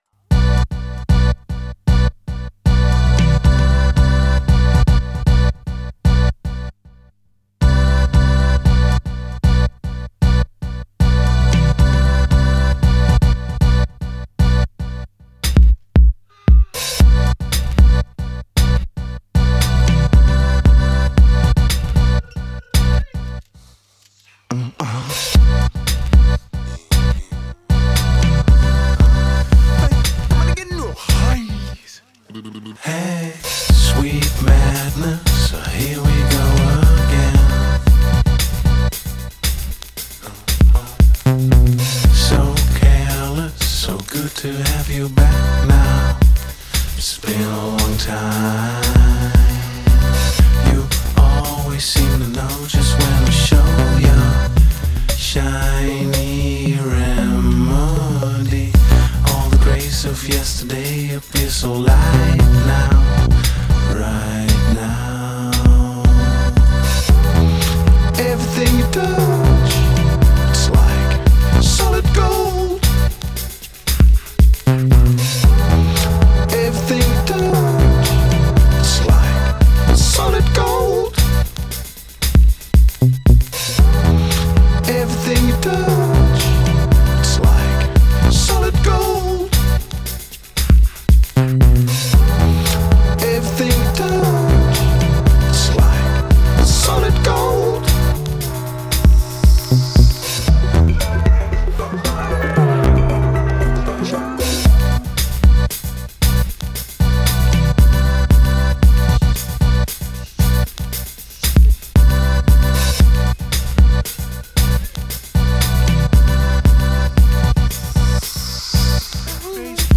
Minimal electronic pulses and beats
electronica